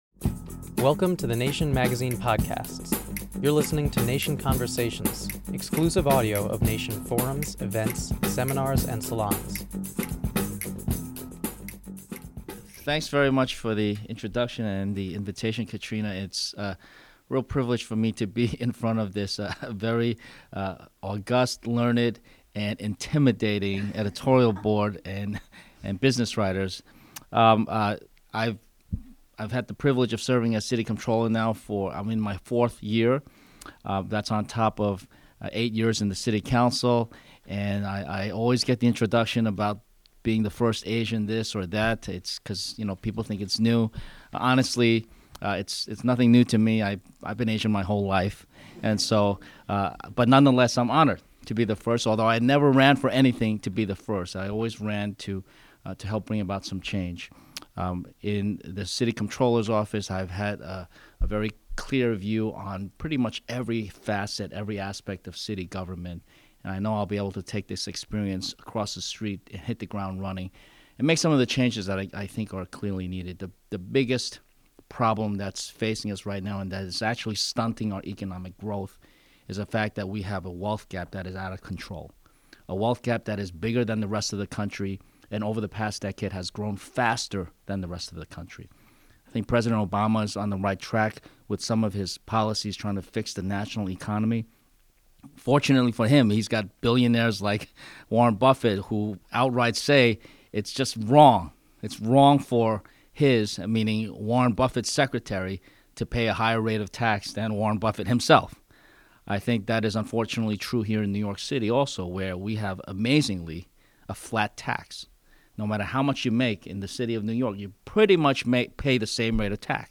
“The biggest problem that’s facing us right now and that is actually stunting our economic growth is the fact that we have a wealth gap that is out of control,” New York City Mayoral candidate John Liu said in a recent conversation with Nation correspondents, editors and staff.
This is the first in a series of conversations with New York’s mayoral candidates.